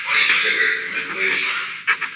Secret White House Tapes
Conversation No. 917-1
Location: Oval Office
The President met with an unknown man.